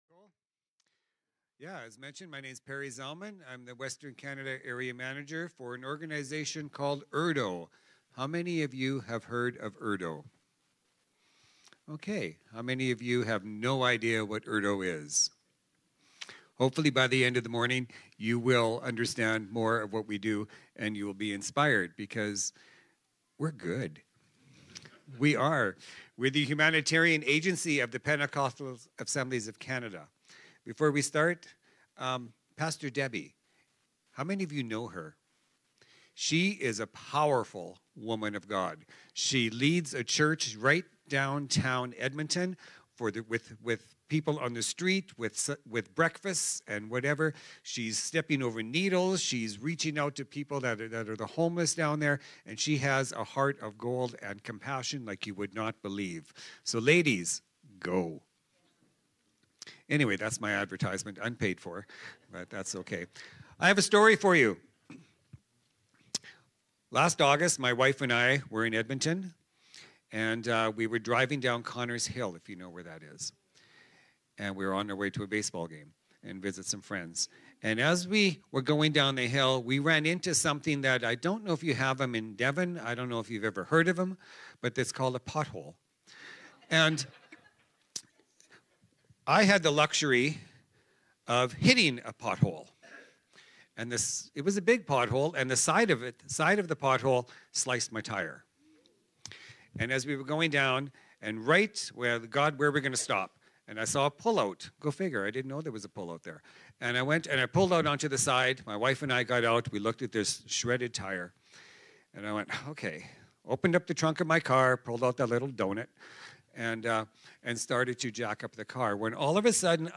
Sermons | Devon Community Church